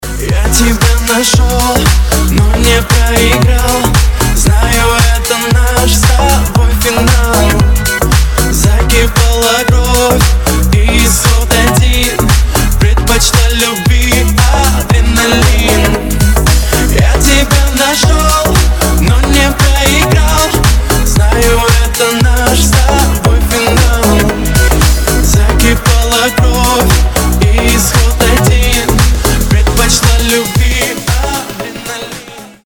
• Качество: 320, Stereo
мужской вокал
deep house
dance